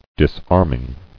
[dis·arm·ing]